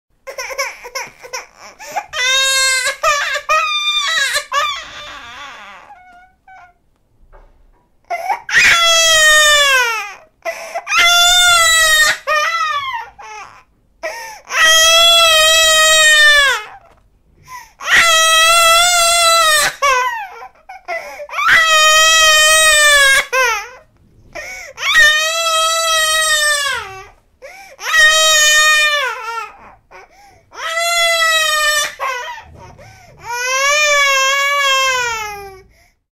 دانلود آهنگ گریه بچه 3 از افکت صوتی انسان و موجودات زنده
دانلود صدای گریه بچه 3 از ساعد نیوز با لینک مستقیم و کیفیت بالا
جلوه های صوتی